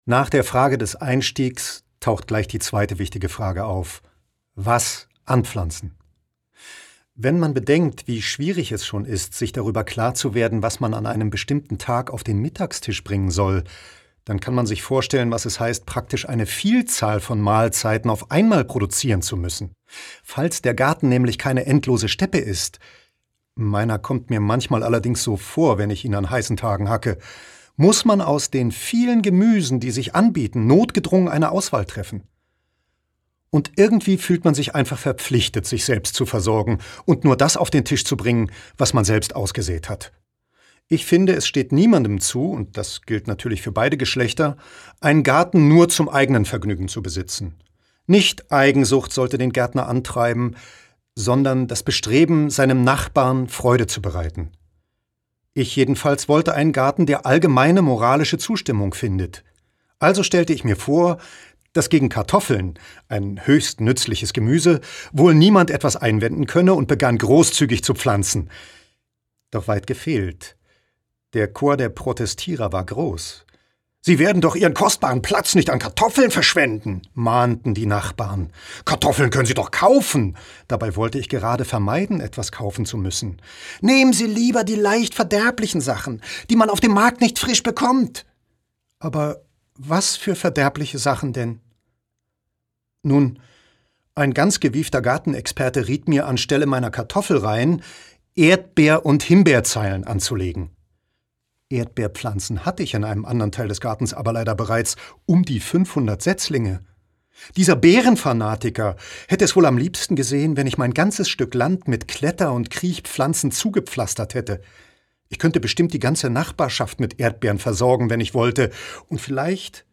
Schlagworte 1870 • Charles Dudley Warner • Essays • Garten • Garten; Jahreszeiten • Gärtner • Hobbygärtner • Hörbuch; Literaturlesung • Humor • Marc Twain • Scholle • Unkraut